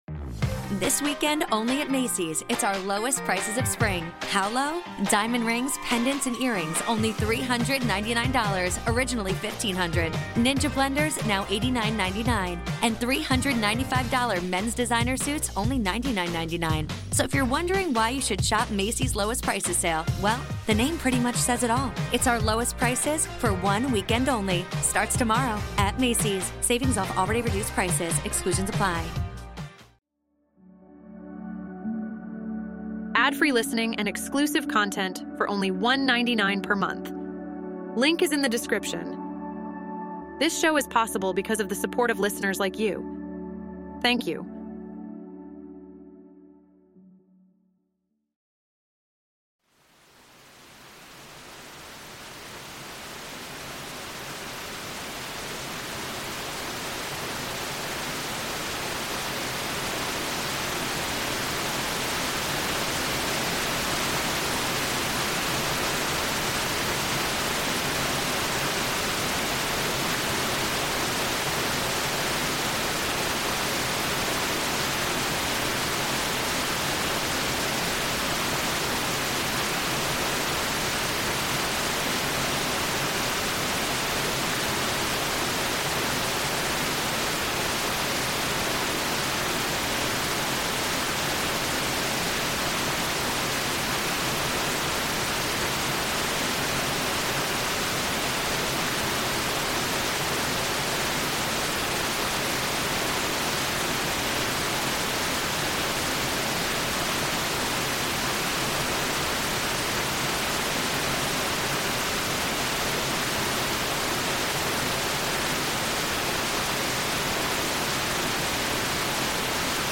Each episode of "White Noise Wednesdays" features a soothing blend of gentle sounds, including the soothing hum of white noise and calming nature sounds.